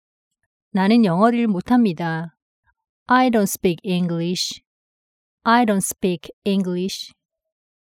l 아이돈 스삐익 이잉글리시ㅣ